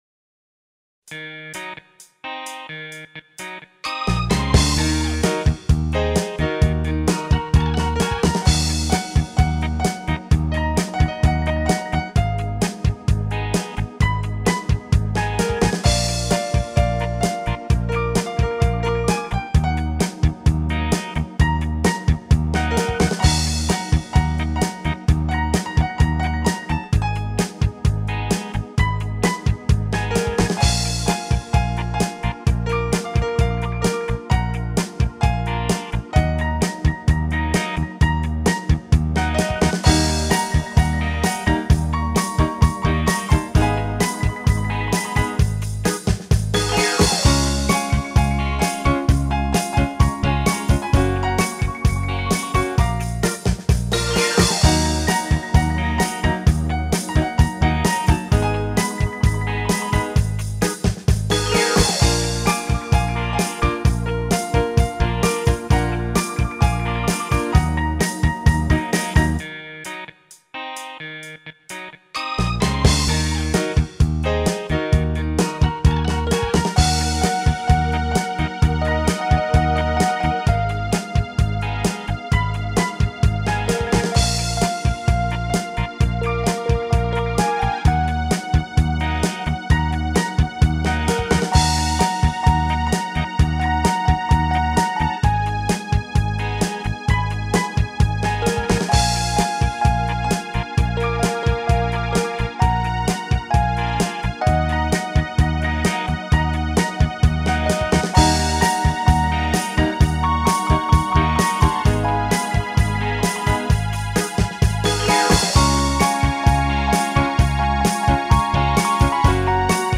Это просто из опыта освоения синтезатора...